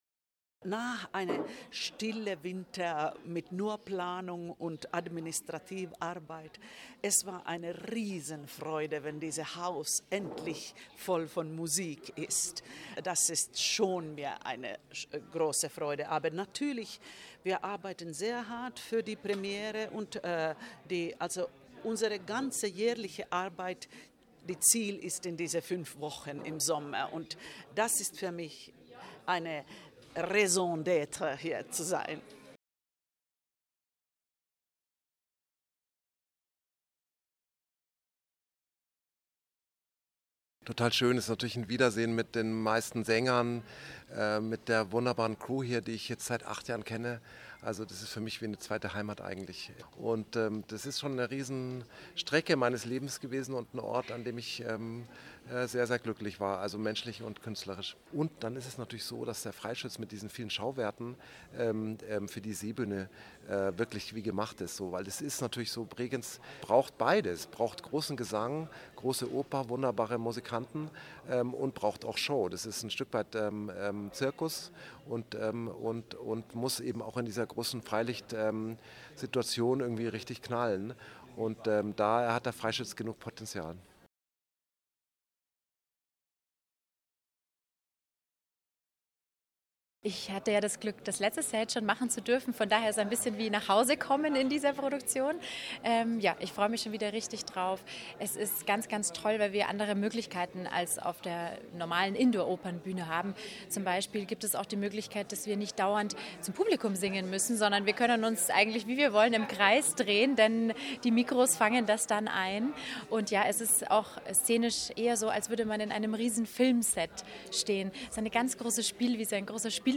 O-Töne Pressetag 2025 Feature